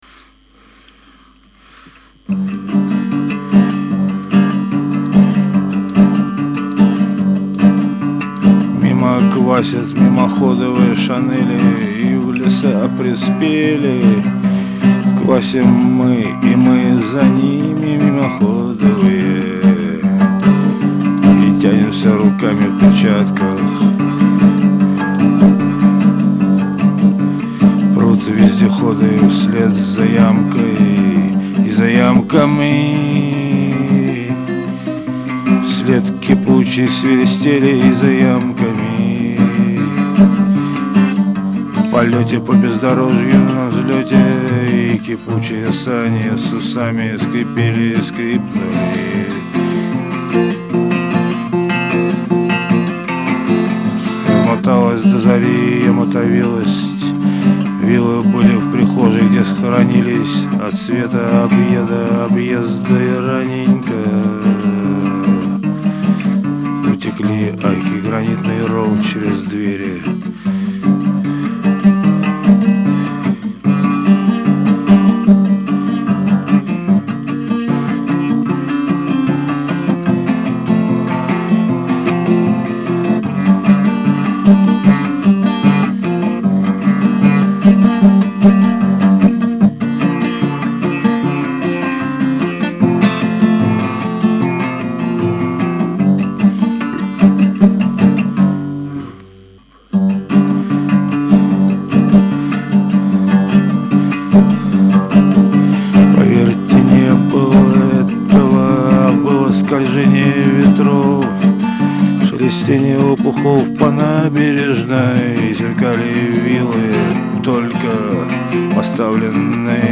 ритмично